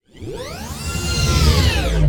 hangar2.ogg